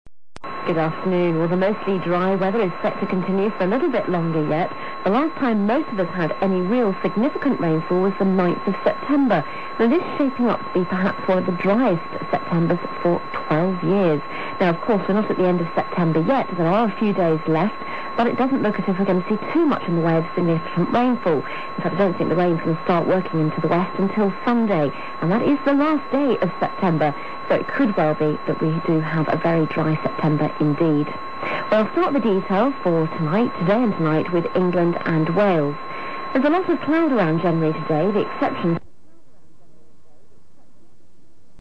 Received Pronunciation
The reference accent of England, the standard in pronunciation if you like, is what is termed Received Pronunciation (a term deriving from the phonetician Daniel Jones at the beginning of the 20th century) and labelled RP for short.
RP is spoken natively by only a small percentage of people in England and by virtually none in other parts of the British Isles, let alone in the rest of the anglophone world.
England_RP.wav